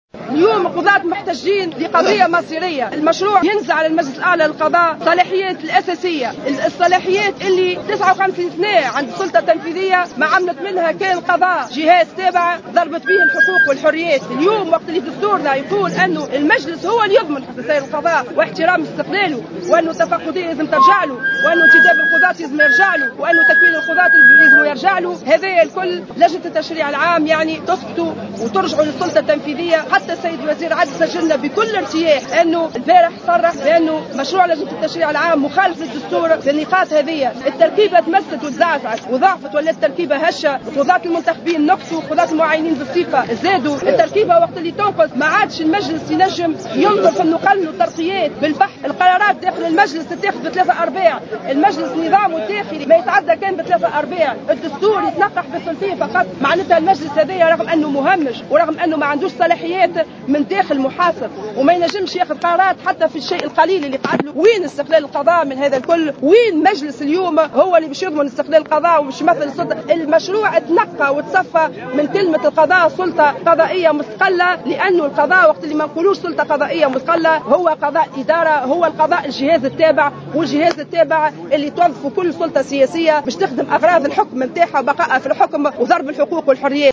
تزامنا مع إضرابهم الذي يتواصل الى غاية يوم الجمعة نفذ القضاة اليوم الأربعاء وقفة احتجاجية امام مجلس نواب الشعب احتجاجا على تركبية المجلس الأعلى للقضاء.